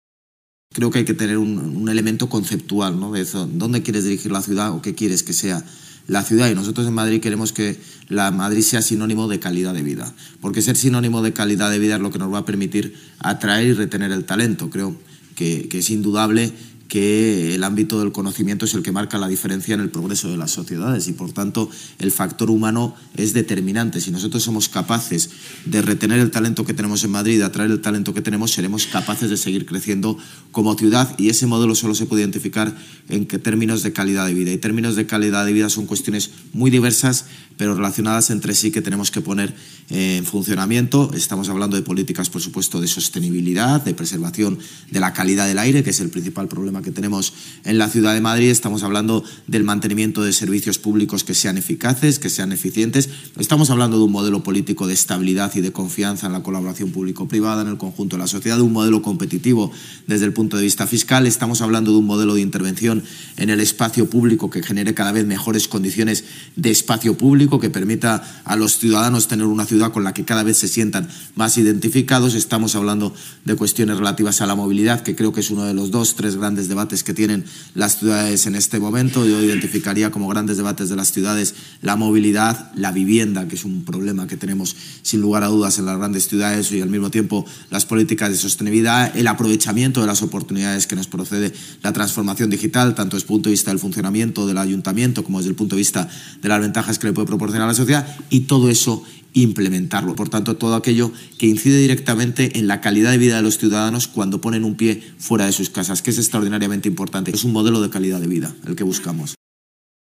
Durante el coloquio con el alcalde de Lisboa, Carlos Moedas, en el Foro La Toja-Vínculo Atlántico
Nueva ventana:José Luis Martínez-Almeida, alcalde de Madrid